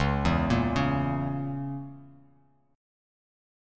Dbm6 chord